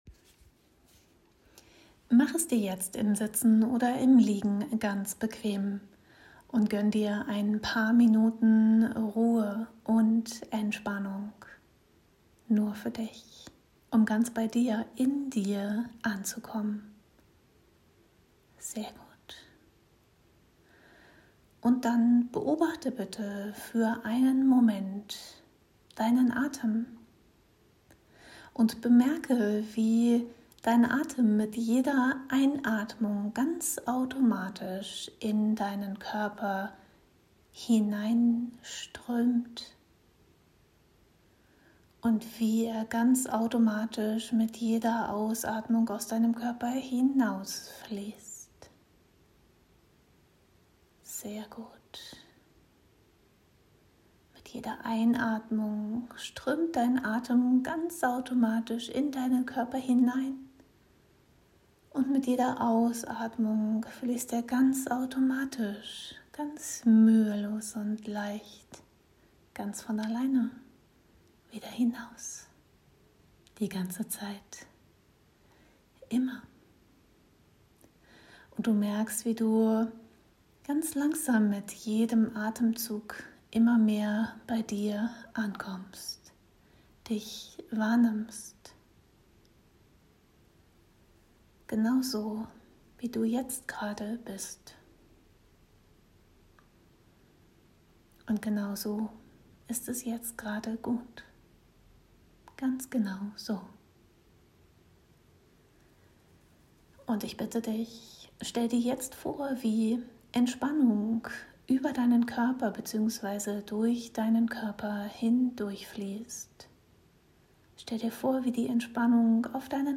Audio Hypnose